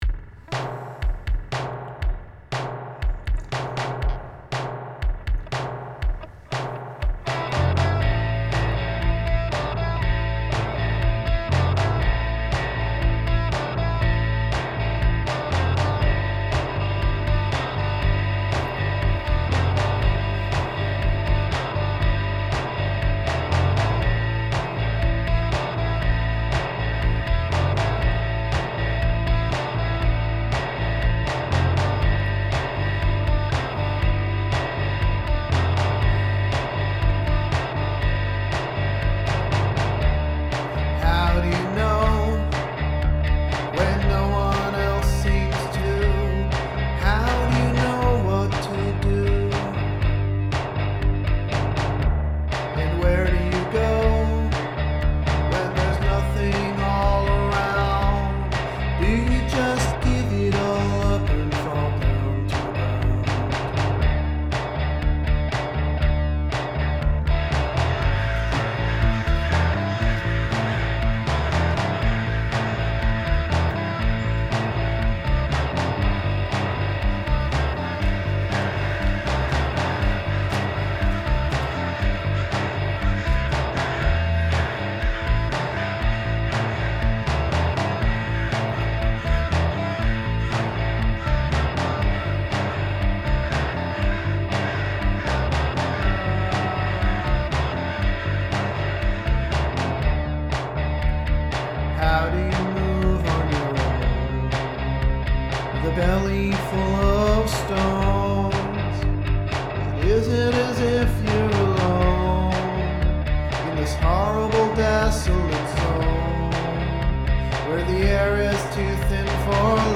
Noisy Vocal and Instrumental Pop Music
guitar, vocal, electronics